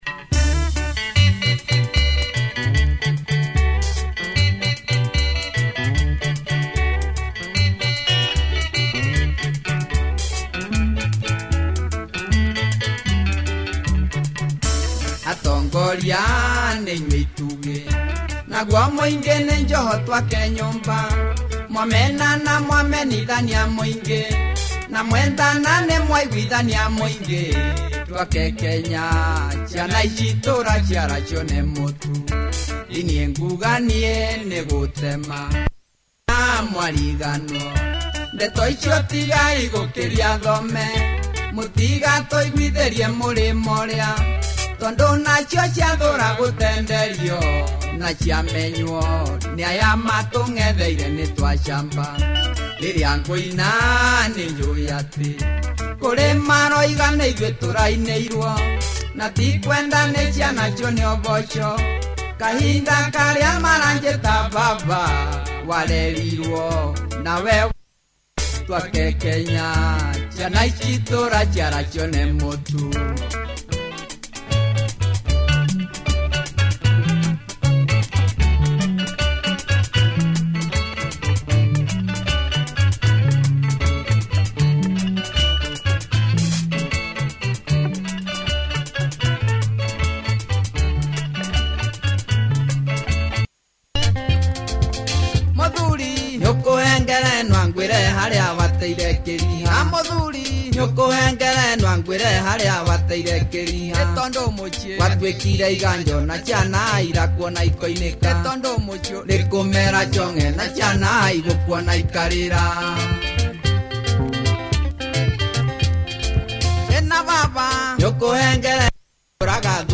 Loud crisp Benga double sider